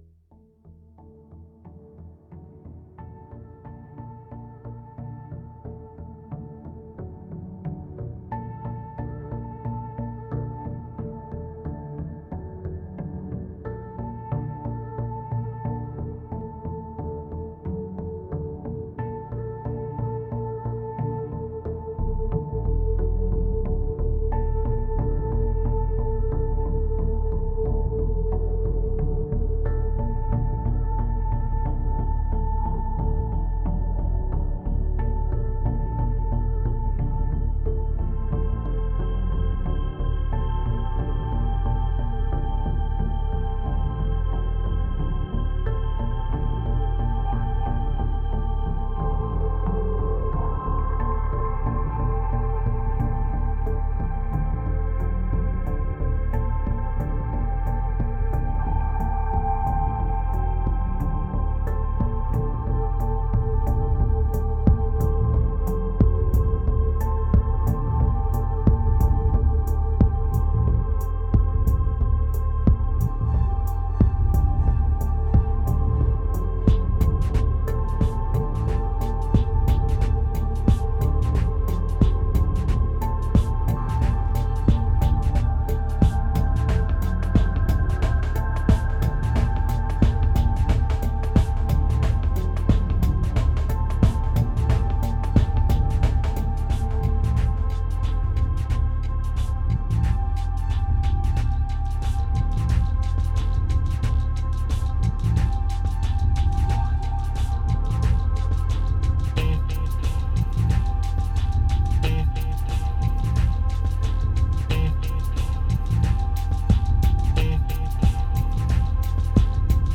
2513📈 - 5%🤔 - 90BPM🔊 - 2011-01-28📅 - -214🌟